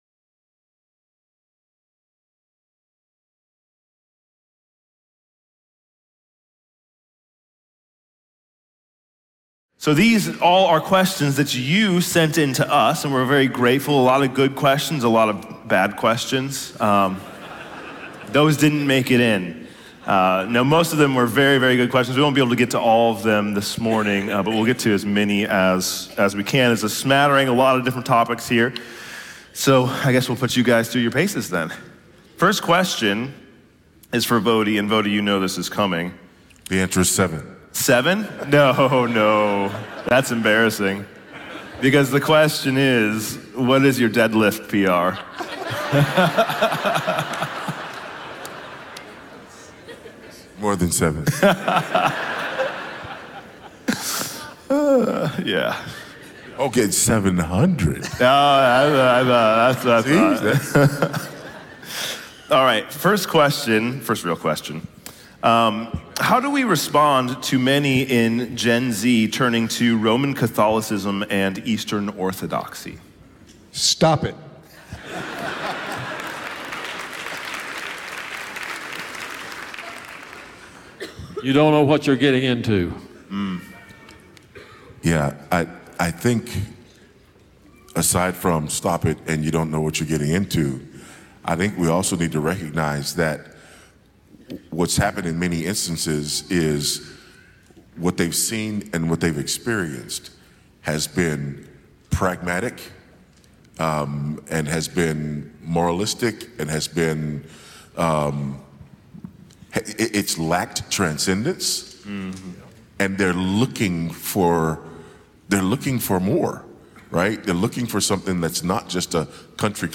Q&A Panel Discussion